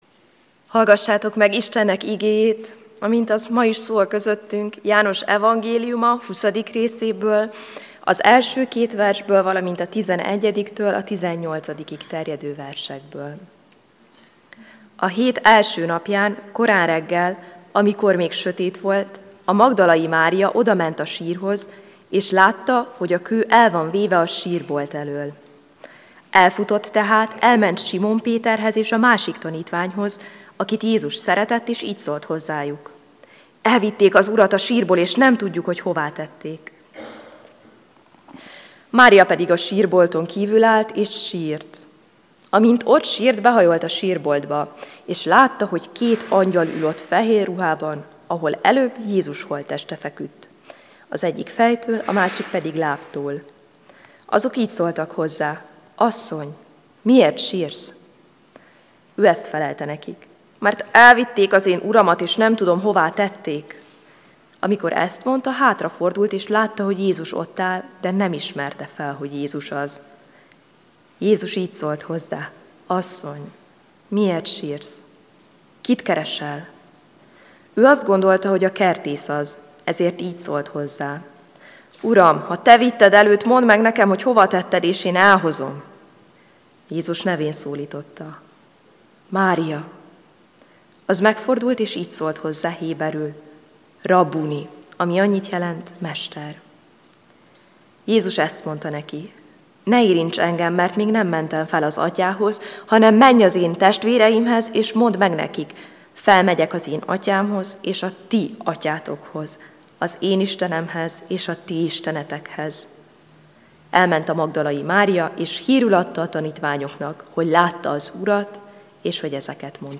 AZ IGEHIRDETÉS LETÖLTÉSE PDF FÁJLKÉNT AZ IGEHIRDETÉS MEGHALLGATÁSA
Húsvét hétfő